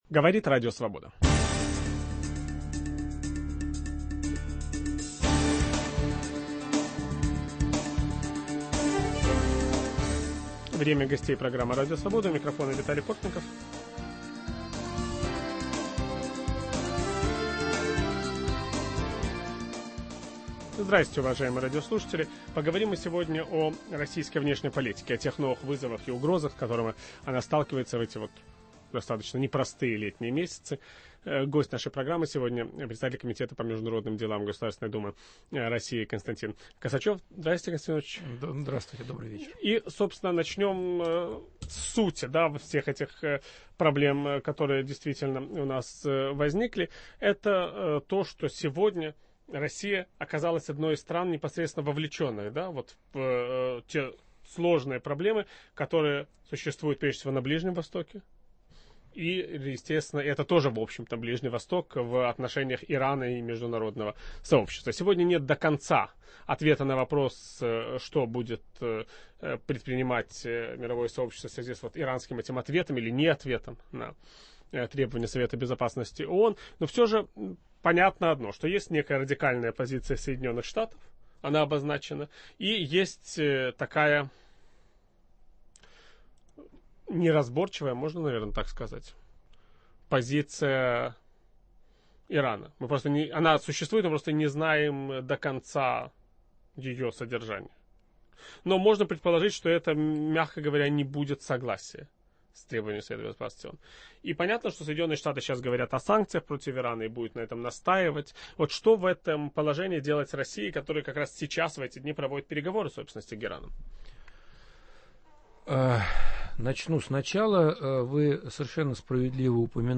Новые угрозы и российская внешняя политика. Гость программы - председатель Комитета по международным делам Госдумы Федерального собрания России Константин Косачев.